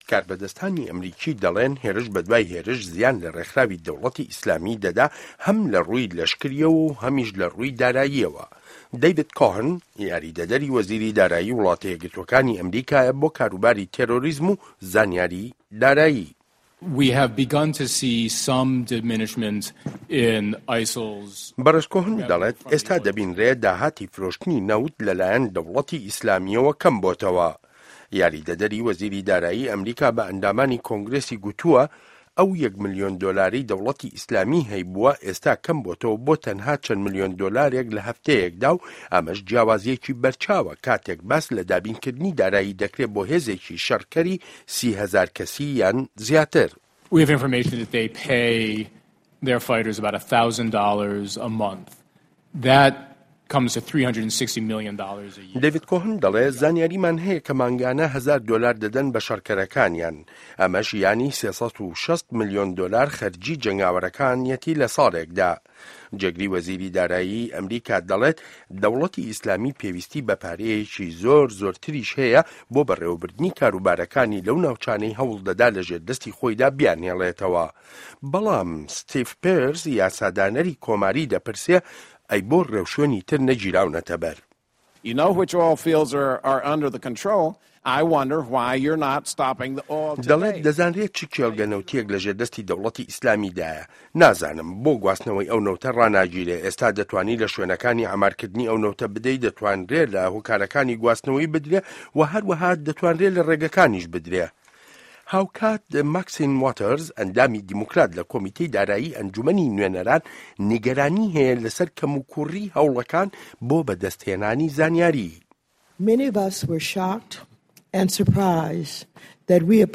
ڕاپۆرتی داهاتی ده‌وڵه‌تی ئیسلامی ڕوو له‌ که‌مبوونه‌وه‌یه